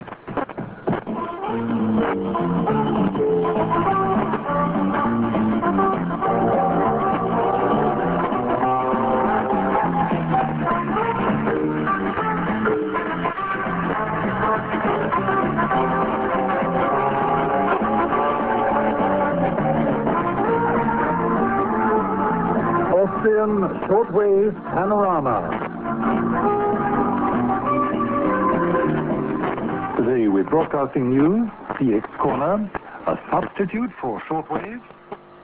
DX programs